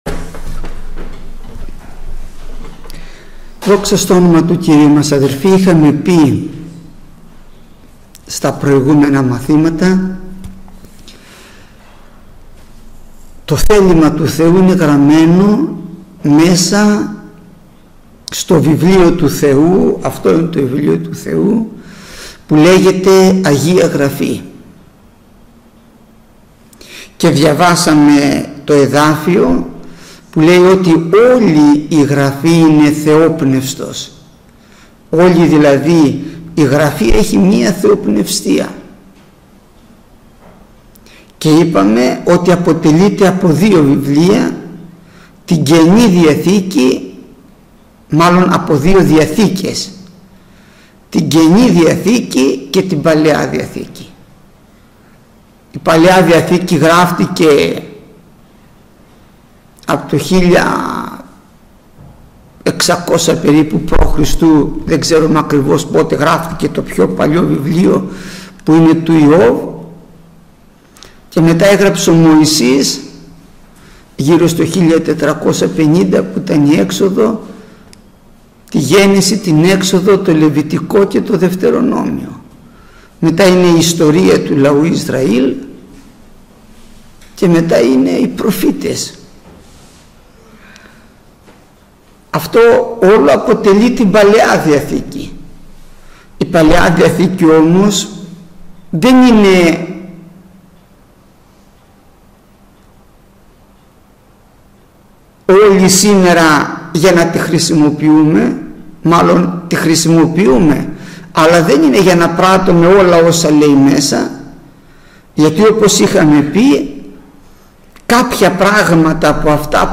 Γεννηθήτω το θέλημά σου (Παλαιά και Καινή διαθήκη) – Μάθημα 131ο